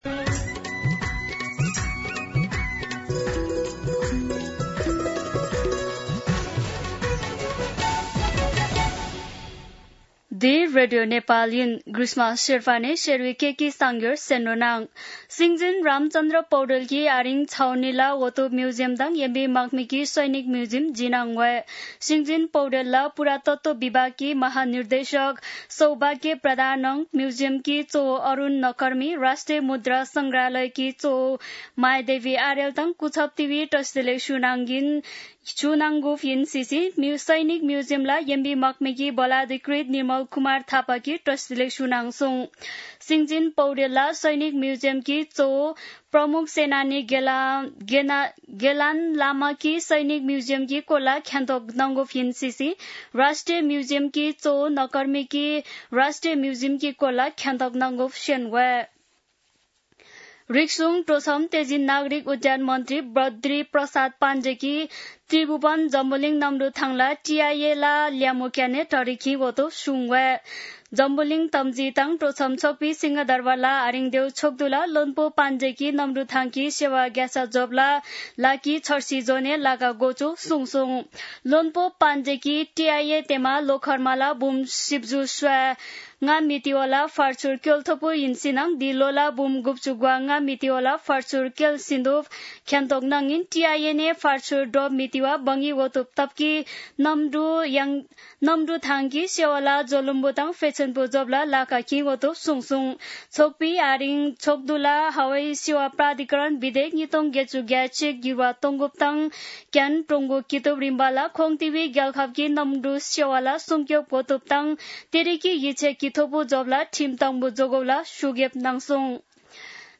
शेर्पा भाषाको समाचार : २२ असार , २०८२
Sherpa-News-22.mp3